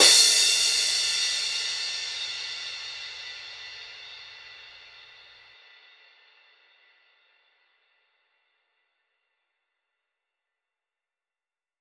Crashes & Cymbals
edm-crash-03.wav